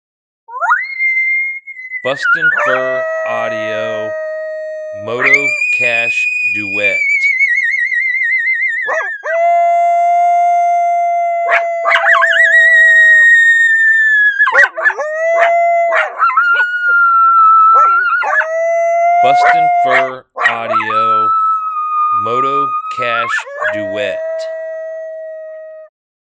Pair of 2 year old Male Coyotes howling together with building intensity.
• Product Code: pair howls